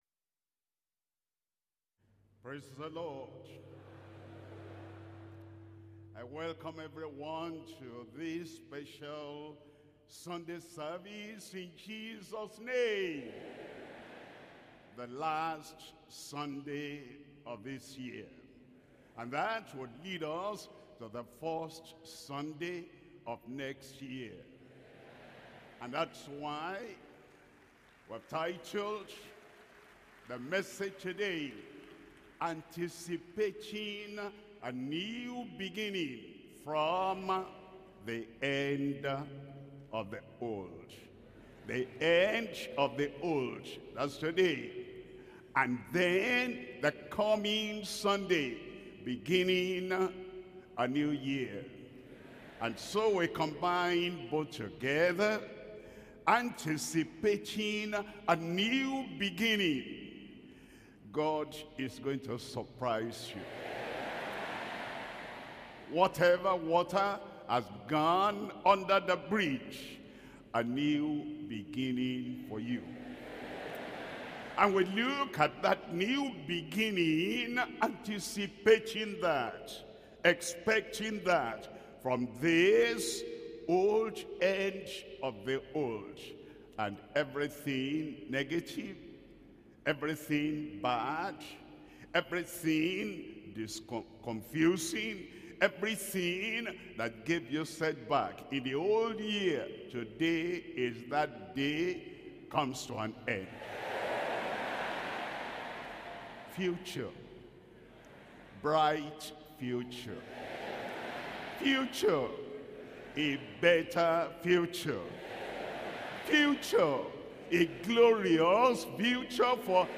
SERMONS – Deeper Christian Life Ministry Australia
Sunday Worship Service